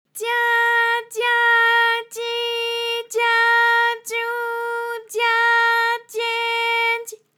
ALYS-DB-001-JPN - First Japanese UTAU vocal library of ALYS.
dya_dya_dyi_dya_dyu_dya_dye_dy.wav